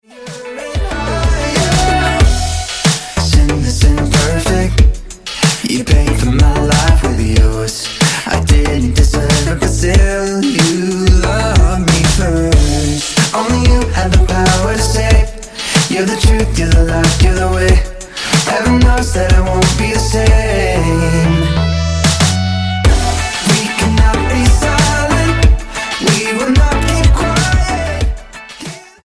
STYLE: Pop
hugely catchy pop clearly targeted at the young
decidedly funky